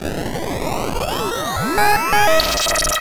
Glitch FX 24.wav